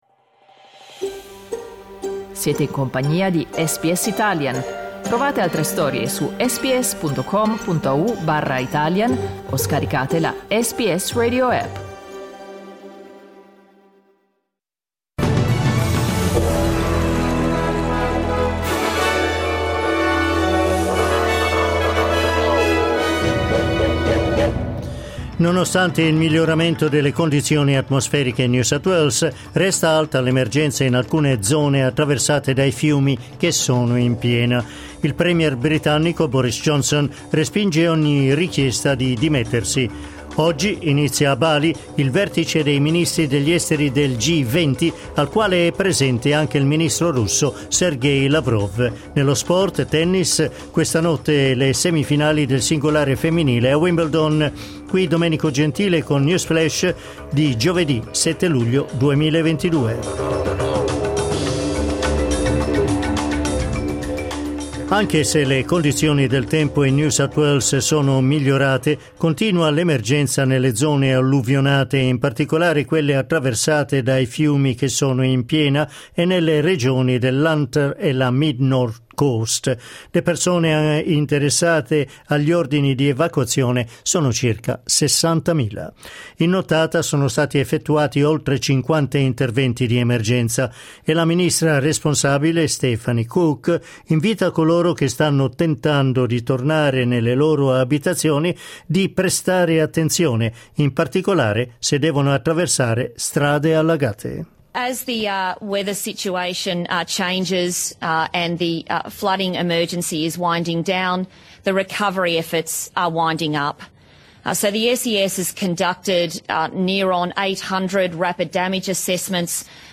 News flash giovedì 7 luglio 2022